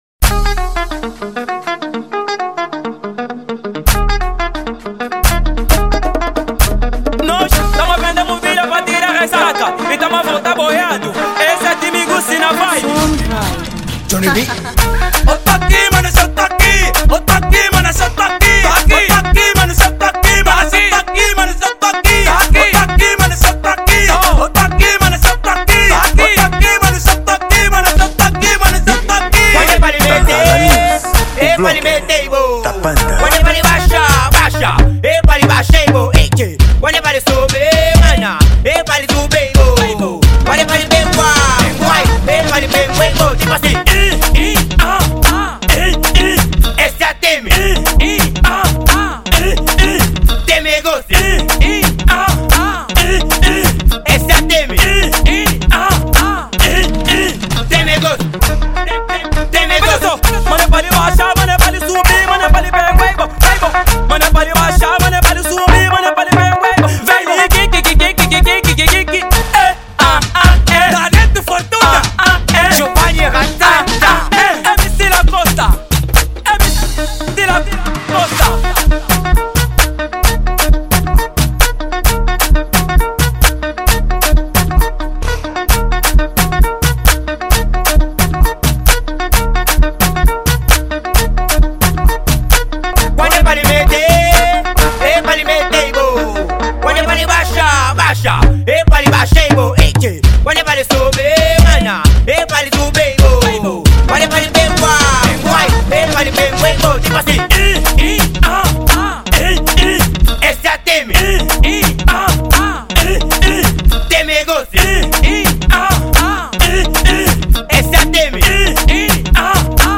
Género: Afro house